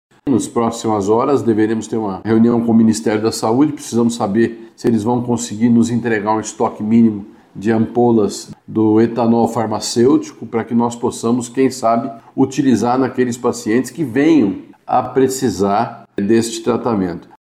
O secretário da Saúde do Paraná, Beto Preto, falou que está em contato com o Ministério da Saúde para o recebimento do antídoto utilizado no tratamento de intoxicação por metanol.